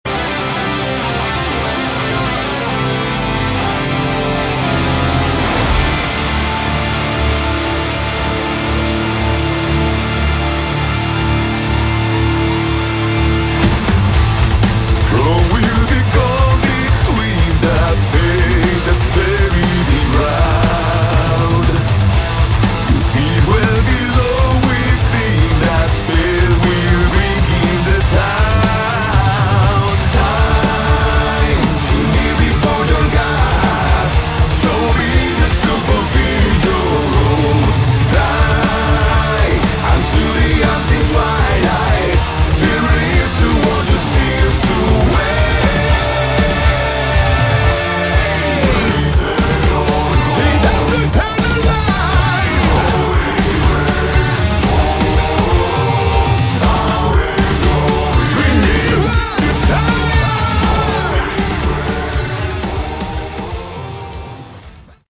Category: Rock
drums
electric bass
electric guitar
vocals